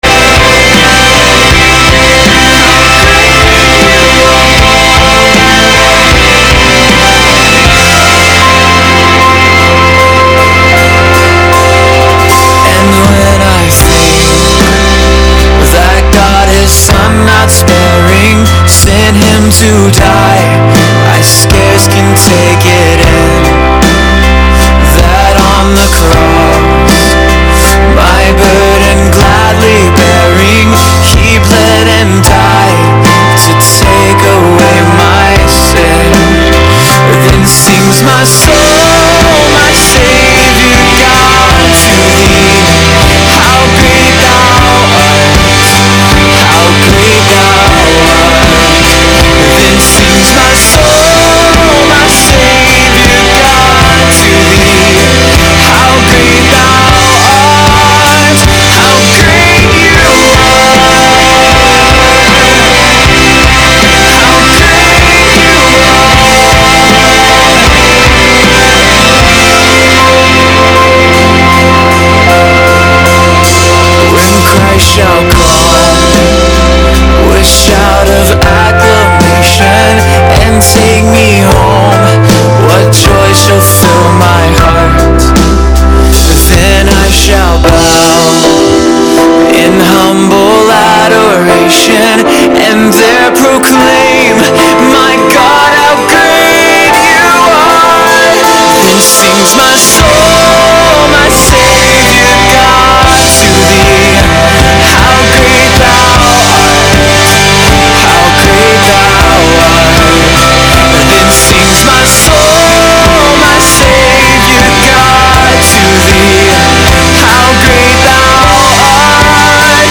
Sermon: “He Was Exalted” (Isaiah 52:13-15)